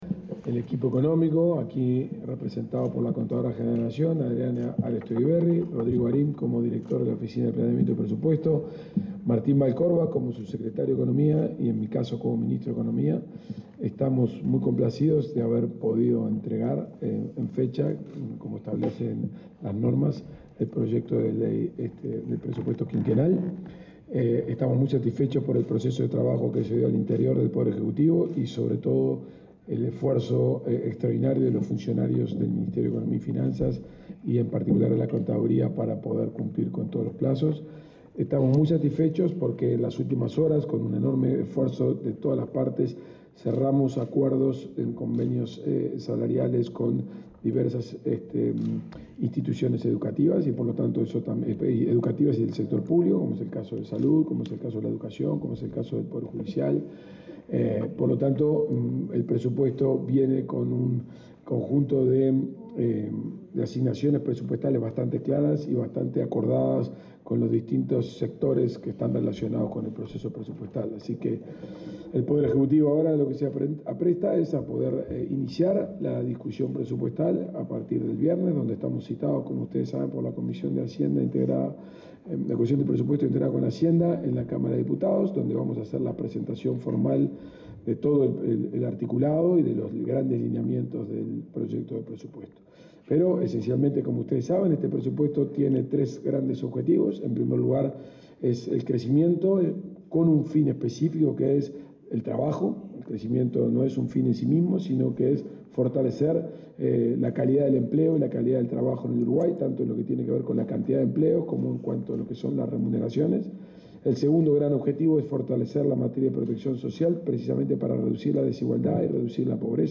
El ministro de Economía y Finanzas, Gabriel Oddone, realizó una conferencia de prensa, luego de entregar el proyecto de Ley de Presupuesto Nacional